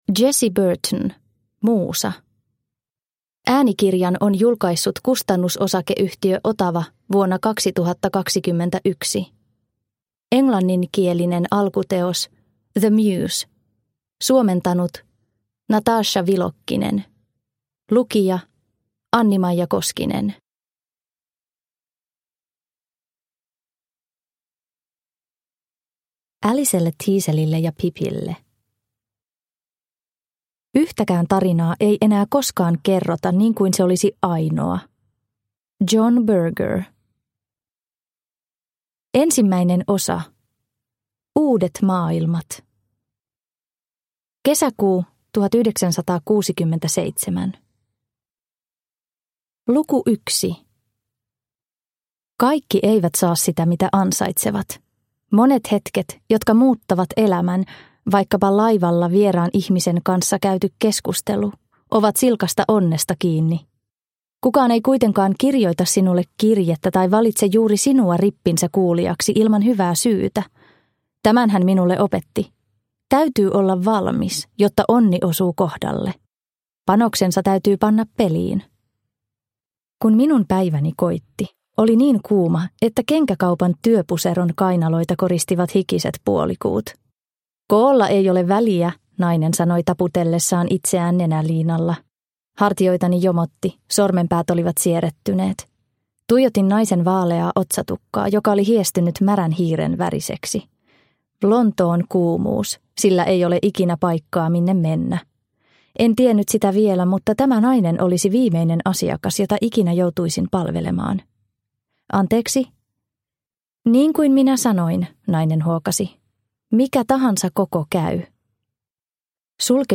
Muusa – Ljudbok – Laddas ner